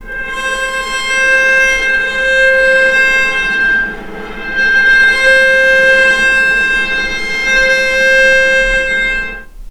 vc_sp-C5-mf.AIF